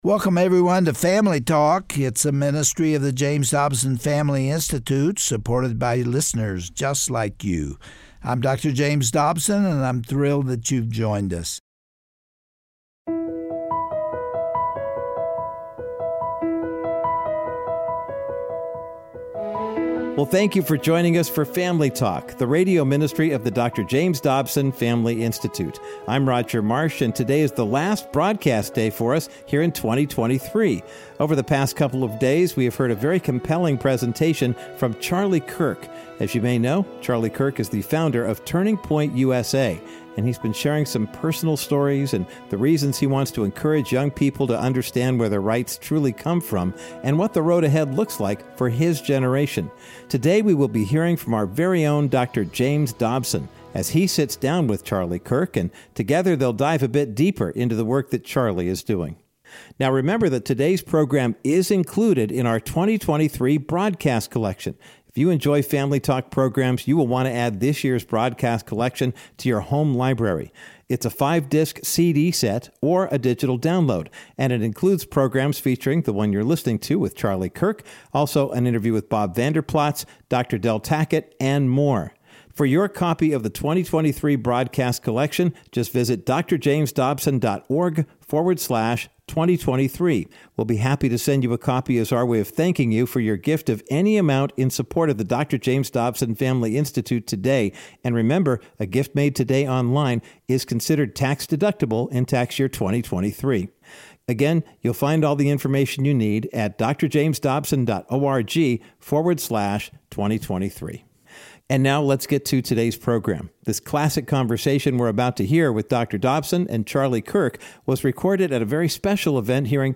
On today’s edition of Family Talk, Dr. James Dobson interviews Charlie Kirk, the founder of Turning Point USA. They discuss the importance of reaching young people and encouraging them to step into the culture to make a difference with the gospel of Jesus Christ. When asked if there is a hunger for the Good News on college campuses today, Charlie said that beneath the noise and clamor of secular society, there are many who are looking for hope and a Savior.